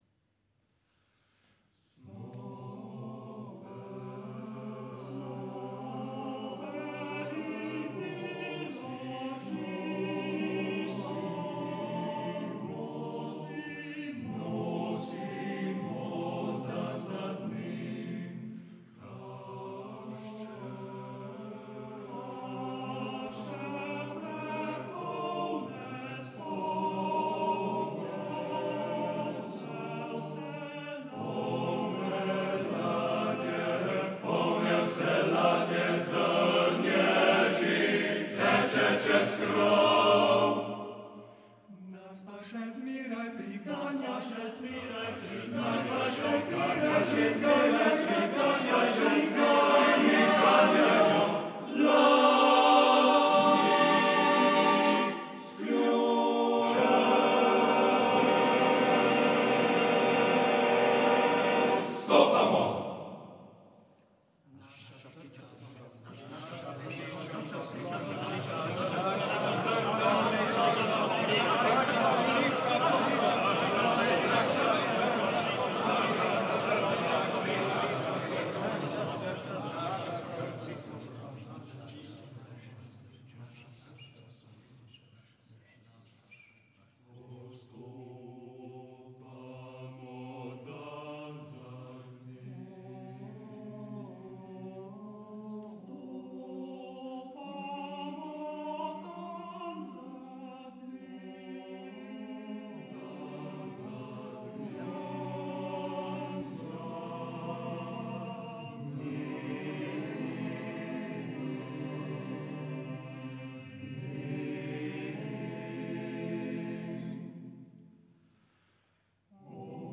24. zborovsko tekmovanje Naša pesem 2016 za odrasle pevske zasedbe iz Slovenije in slovenske zasedbe iz tujine je končano. Po 30 letih so se ga v pomlajeni sestavi ponovno udeležili pevci Komornega moškega pevskega zbora Davorina Jenka Cerklje
Pesem nosačev žita (Lojze Lebič) – KMoPZ Davorina Jenka Cerklje (Avdio: JSKD)